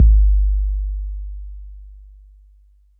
puredata/resources/808_drum_kit/kicks/808-Kicks33.wav at ef802ba0a91310d39afda753910bf055e018f196
808-Kicks33.wav